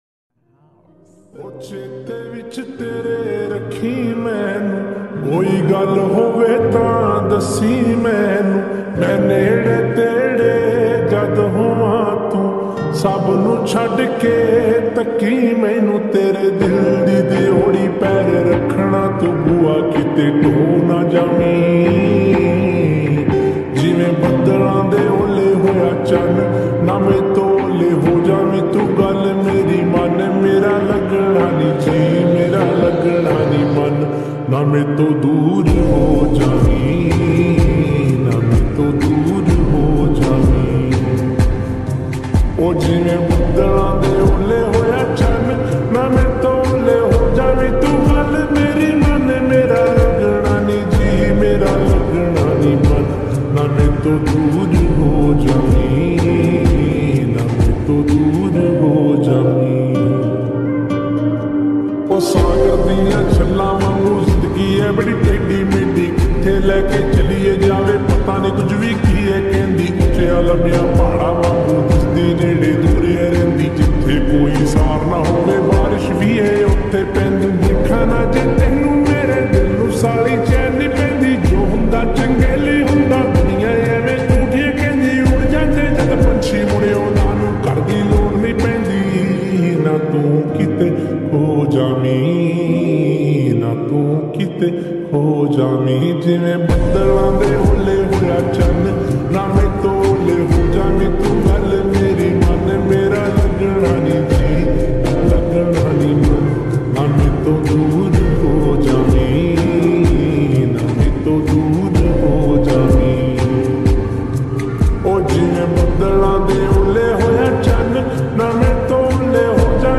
NEW PANJABI SONG